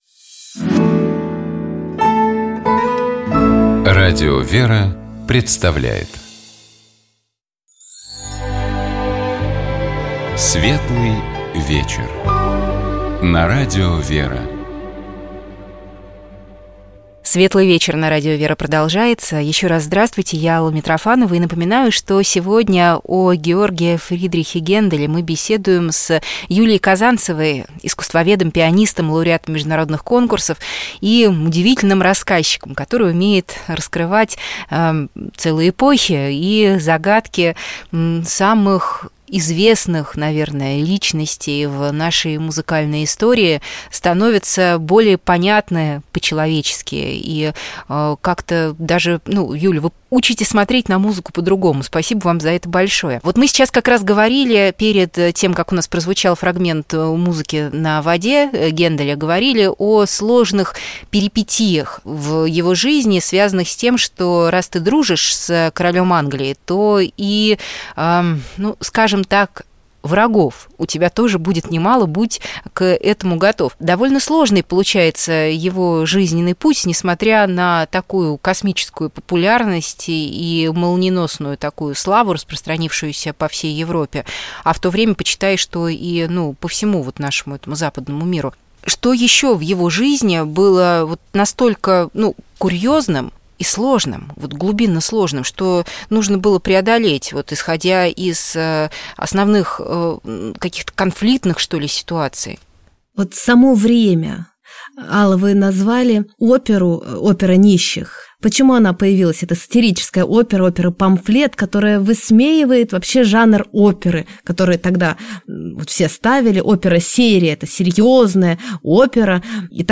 Мы беседовали с кандидатом искусствоведения, пианистом, лауреатом международных конкурсов, автором музыкально-просветительских циклов